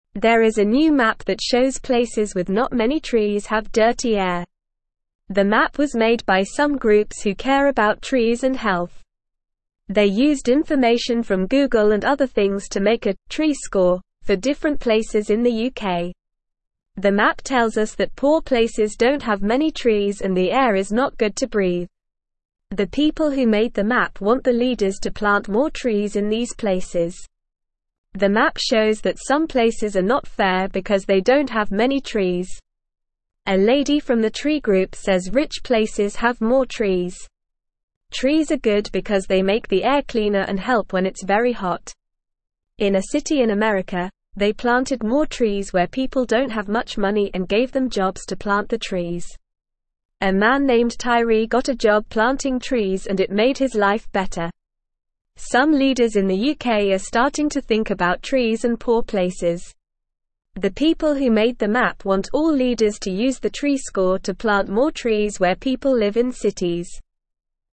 Normal
English-Newsroom-Beginner-NORMAL-Reading-Map-Shows-Places-with-Few-Trees-Have-Dirty-Air.mp3